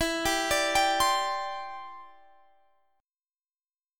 Listen to D7sus2sus4/E strummed